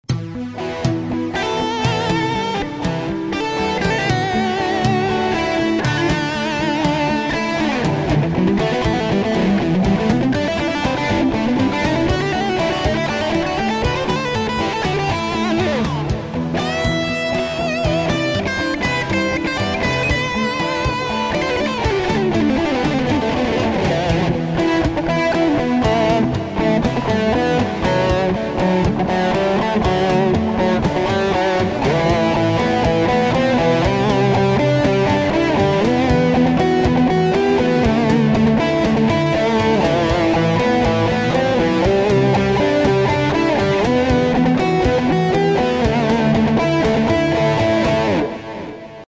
We will try all together to compose a complet track in John Petrucci's style
style néo-classique, heavy...